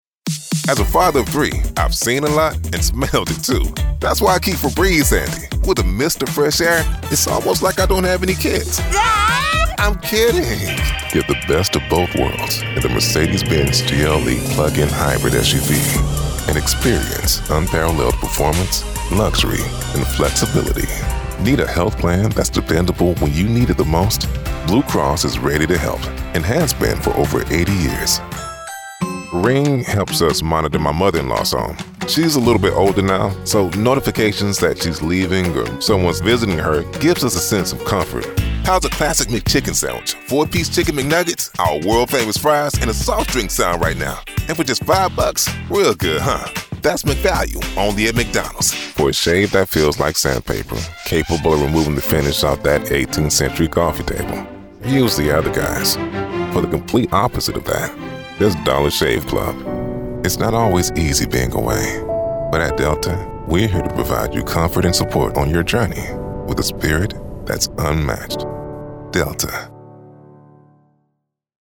1. COMMERCIAL REEL 1:12
Polished. Authentic. Compelling. Bold.